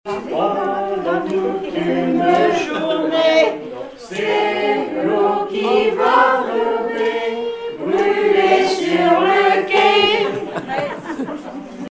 carnaval, mardi-gras
Pièce musicale inédite